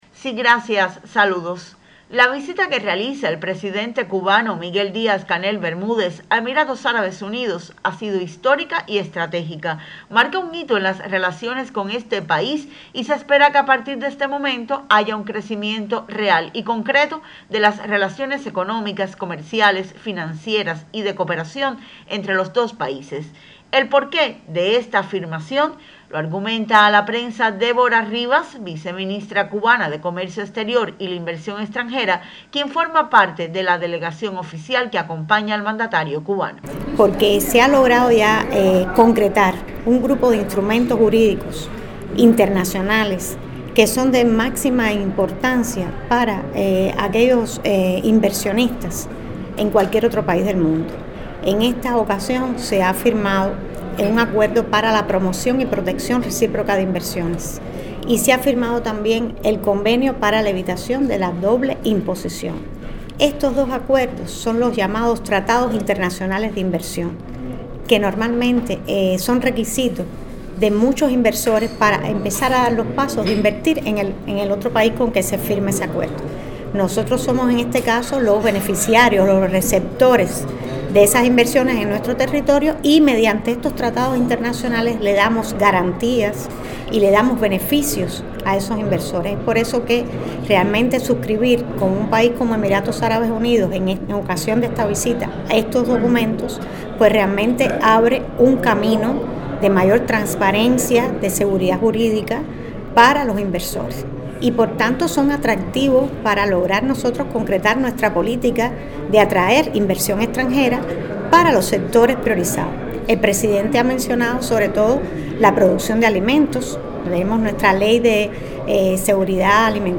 Los acuerdos rubricados durante la visita del Presidente Díaz-Canel tendrán un efecto dinamizador en las relaciones económicas y comerciales, señaló en declaraciones al equipo de prensa de la Presidencia de la República, la viceministra de Comercio Exterior e Inversión Extranjera, Déborah Rivas Saavedra.
viceministra_mincex.mp3